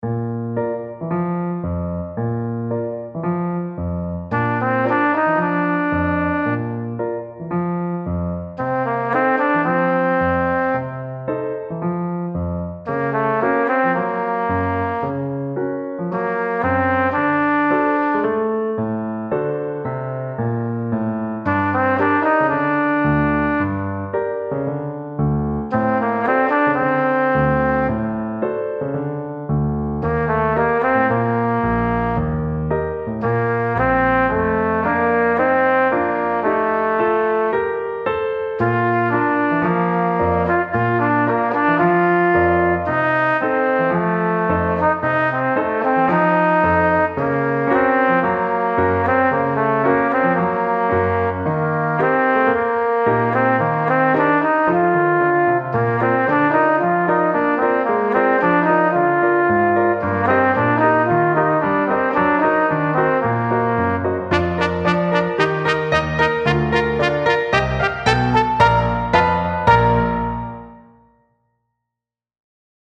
Performance